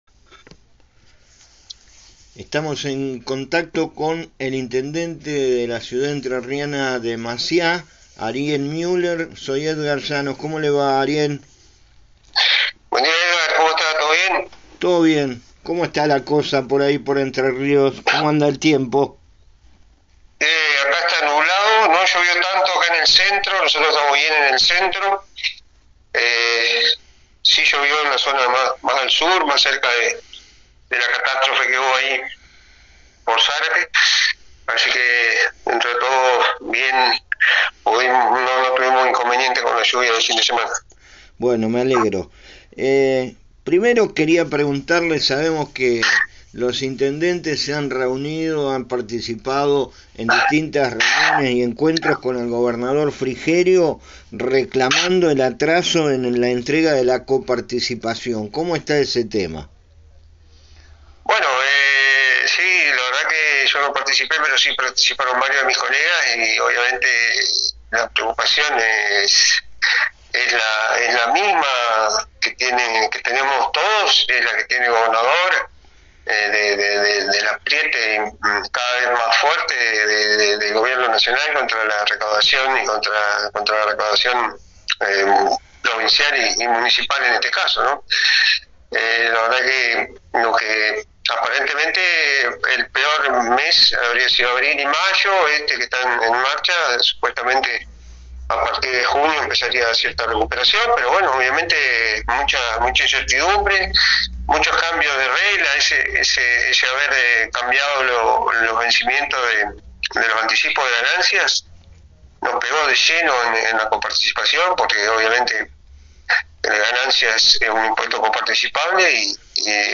Luego de que los intendentes de la provincia se reunieran con el gobernador Frigerio, lo consultamos por el tema de la coparticipación, aclarando que no participó personalmente.